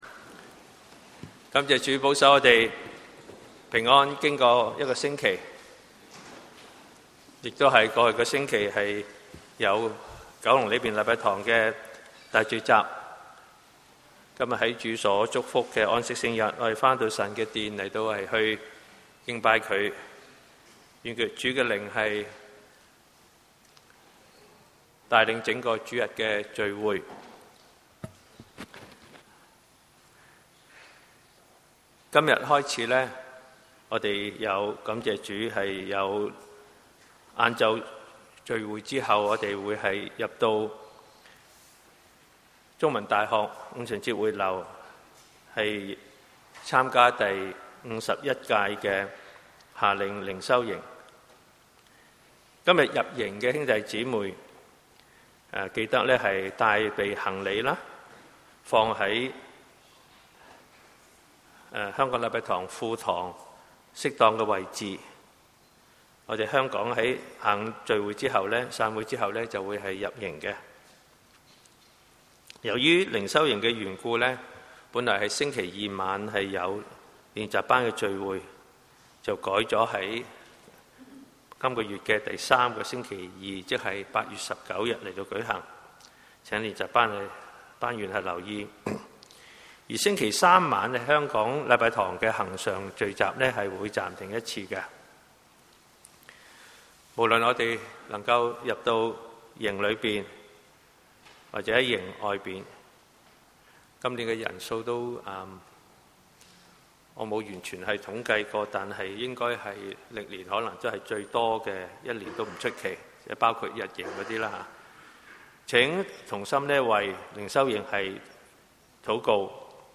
主日崇拜 – 港九五旬節會